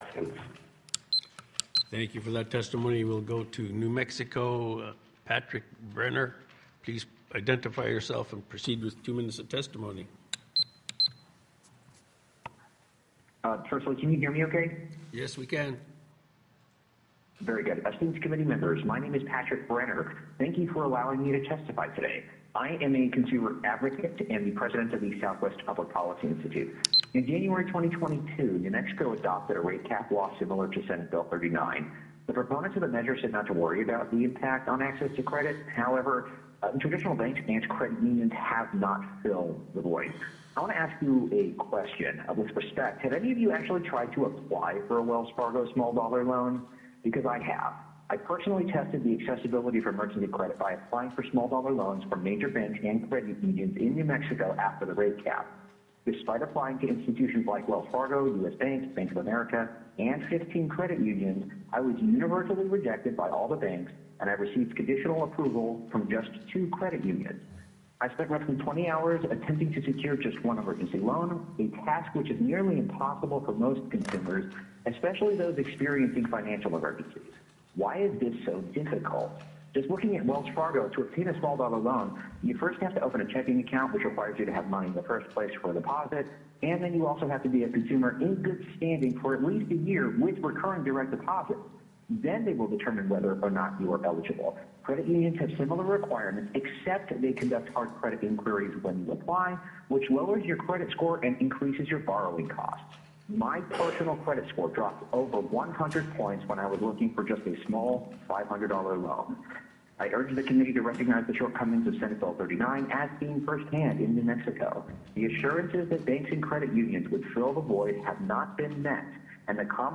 Last week, I had the opportunity to testify before the Alaska Senate Finance Committee on the dangers of Senate Bill 39, a proposal to impose a 36% APR cap on consumer credit.